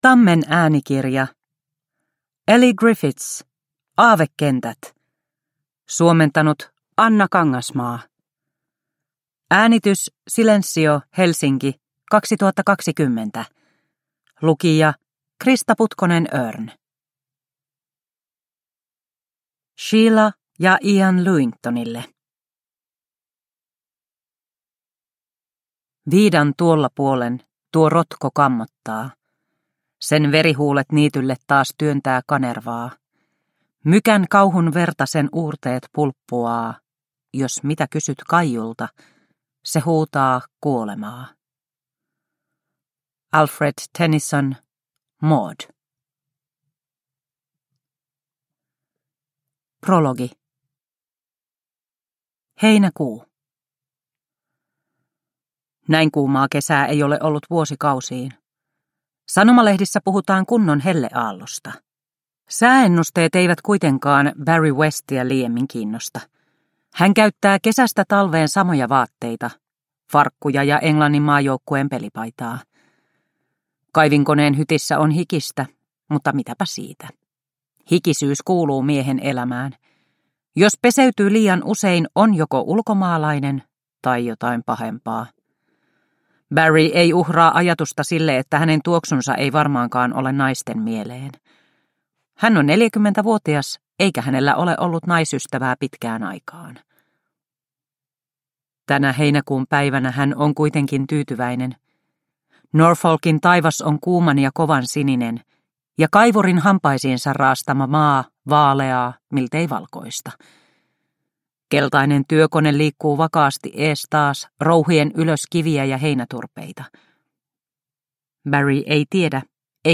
Aavekentät – Ljudbok – Laddas ner